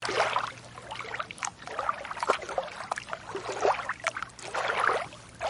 Splashing Hands
Splashing Hands is a free sfx sound effect available for download in MP3 format.
Splashing Hands.mp3